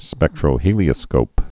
(spĕktrō-hēlē-ə-skōp)